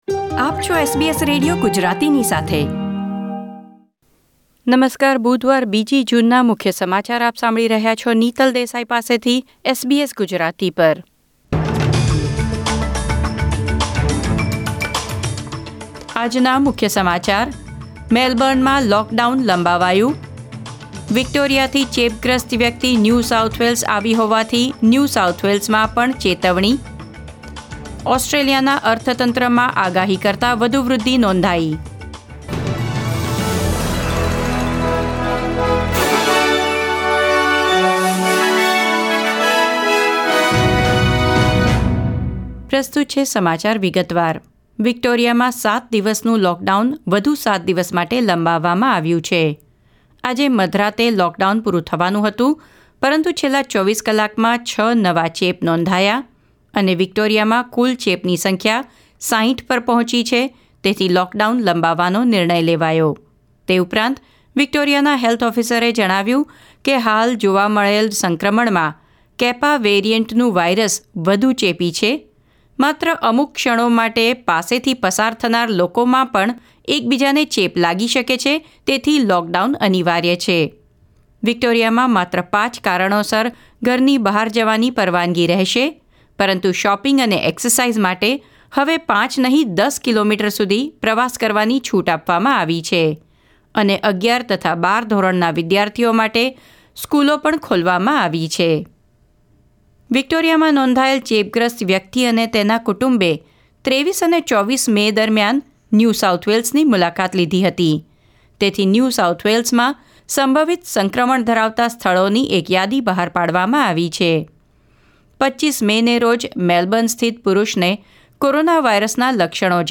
SBS Gujarati News Bulletin 2 June 2021